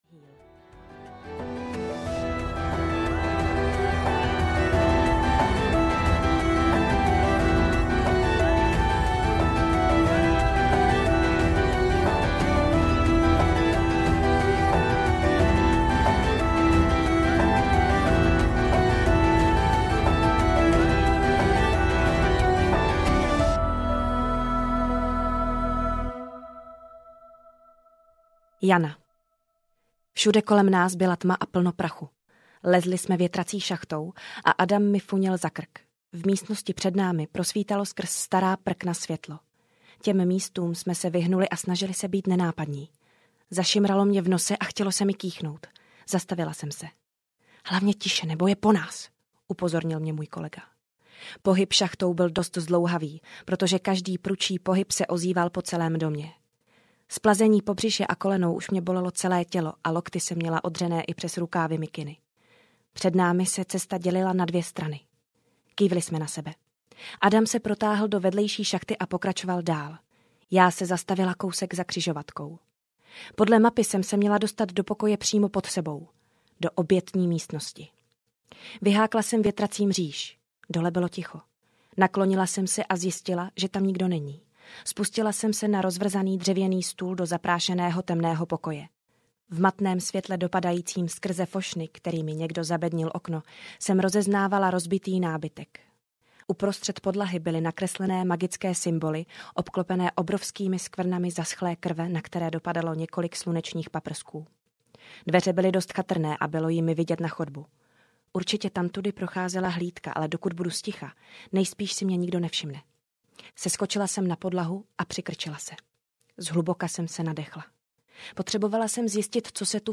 Pán čarodějů audiokniha
Ukázka z knihy
pan-carodeju-audiokniha